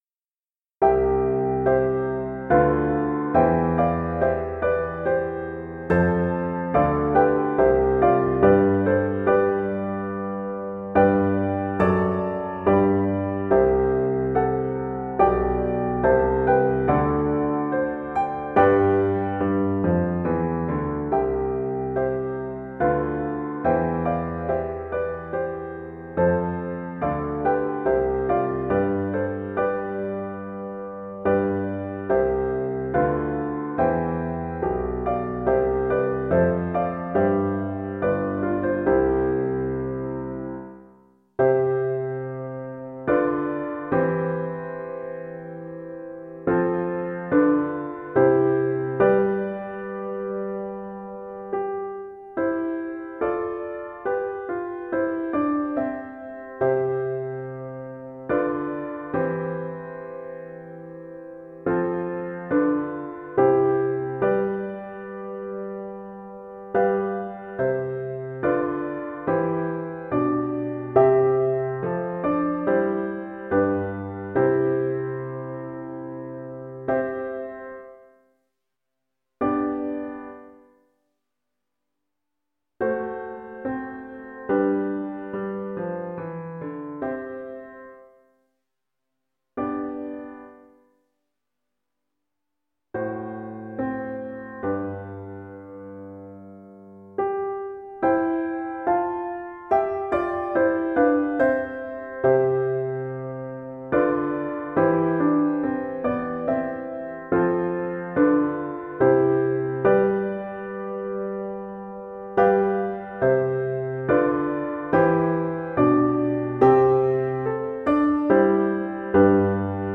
Alto (Treble) Recorder version
Italian Baroque composer.
3/4 (View more 3/4 Music)
Tempo di Minuetto
Classical (View more Classical Alto Recorder Music)